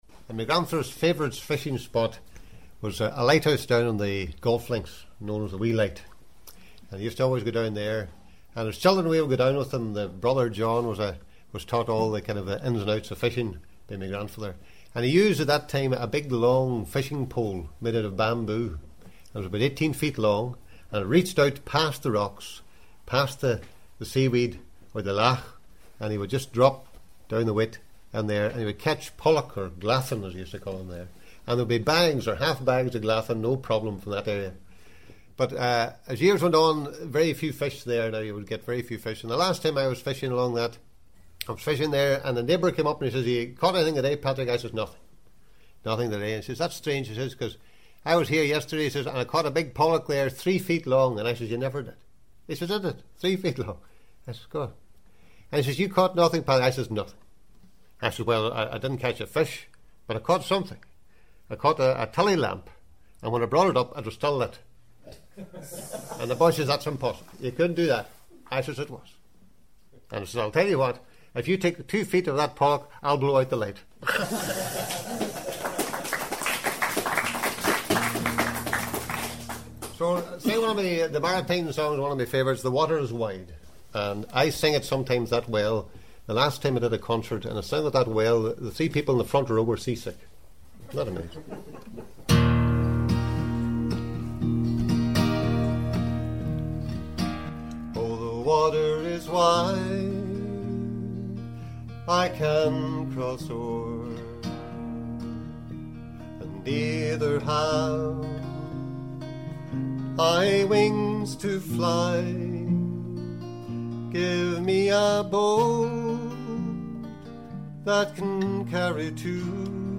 Here, he sings 'The water is wide'.